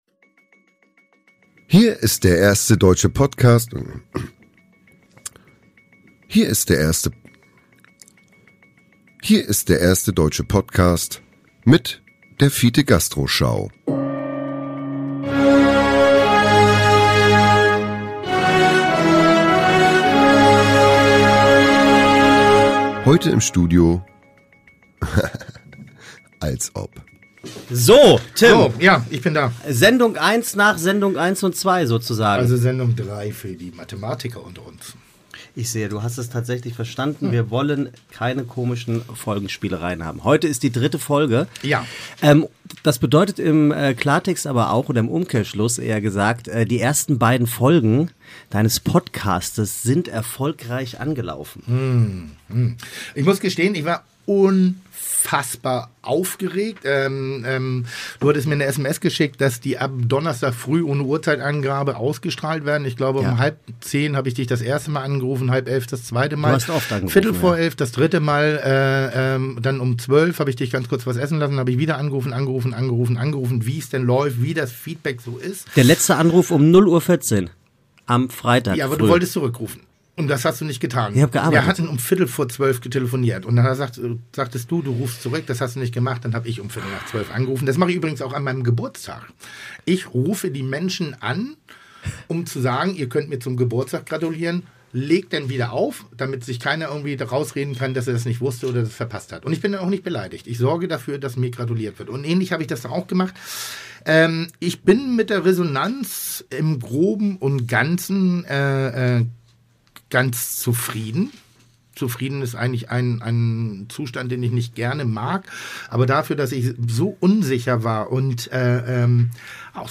Beschreibung vor 6 Jahren Kantinenessen beim NDR oder Resteessen in der Bullerei: Tim und Linda sprechen nicht nur über ihre Erfahrungen beim Fernsehen, sondern auch über den Stellenwert von Essen, über die griechische Küche, Freundschaft und Selbstbewusstsein. Die Stimmung ist ausgelassen, denn wer Linda kennt, weiß, dass sie nicht nur sehr seriös, sondern auch sehr unterhaltsam ist!